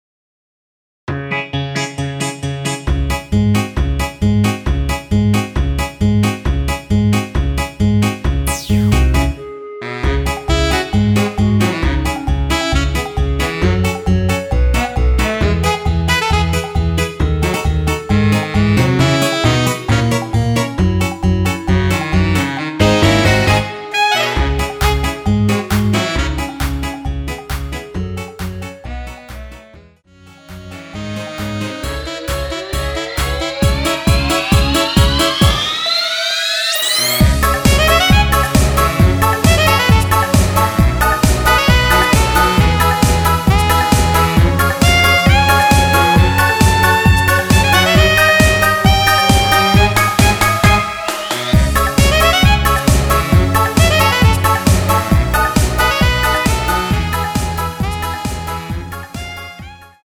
원키에서(-5)내린 멜로디 포함된 MR입니다.
C#m
앞부분30초, 뒷부분30초씩 편집해서 올려 드리고 있습니다.
중간에 음이 끈어지고 다시 나오는 이유는